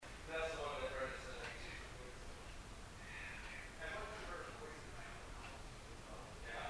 Basement – 9:44 pm
We believe this to be a male voice whispering “Get out of here.”
abc-basement-944pm-whisper.mp3